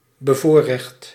Ääntäminen
Ääntäminen France Tuntematon aksentti: IPA: /pʁi.vi.le.ʒje/ Haettu sana löytyi näillä lähdekielillä: ranska Käännös Ääninäyte Adjektiivit 1. bevoorrecht 2. voorrangs- 3. preferent Suku: m .